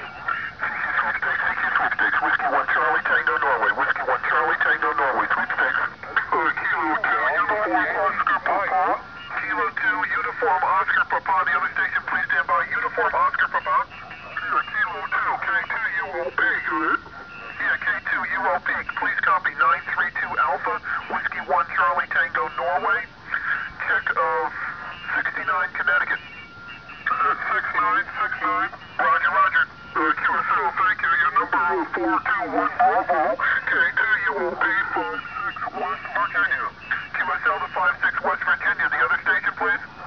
It's perservering the continuous high noise and QRM levels during the event that will bring in a satisfying overall personal score.
Below are several sound files from different contests to give the newcomer an idea of how it's like during the heat of a contest.
Phone Sweepstakes November 1999